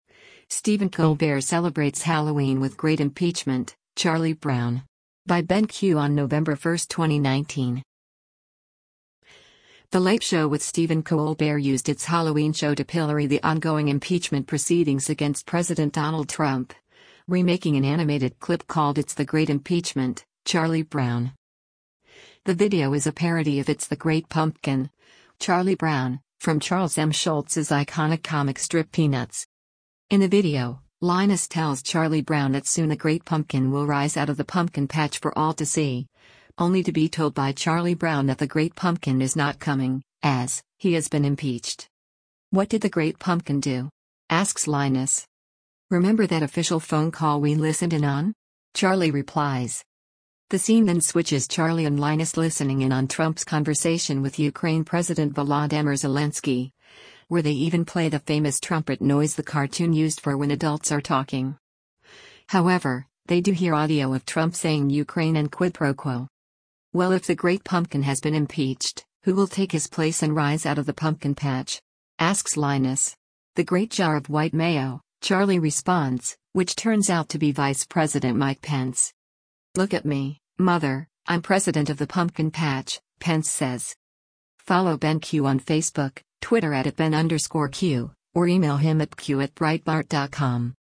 The scene then switches Charlie and Linus listening in on Trump’s conversation with Ukraine President Volodymyr Zelensky, where they even play the famous trumpet noise the cartoon used for when adults are talking. However, they do hear audio of Trump saying “Ukraine” and “quid pro quo.”